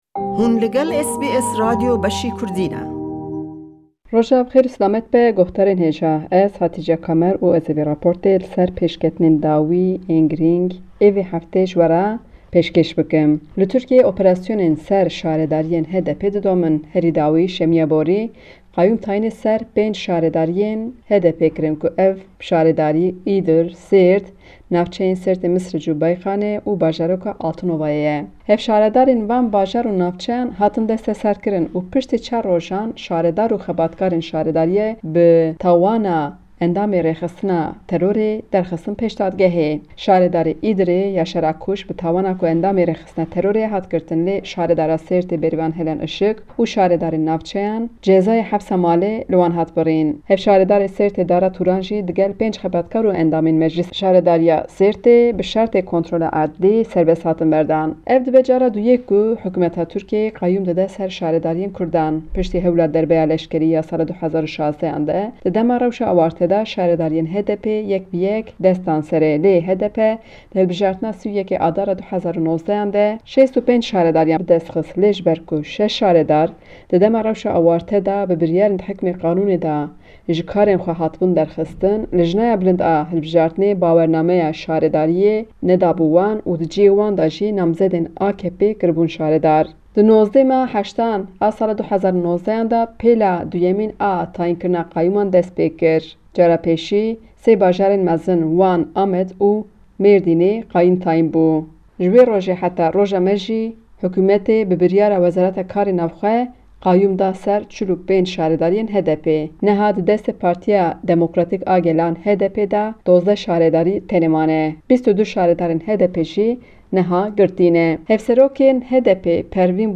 Cîgirê Serokê partîya Sosyalîst yê Kurdîstanê Bayram Bozyel li ser sîyaseta Tirkiyê ya binavkirina qayûman diaxafe.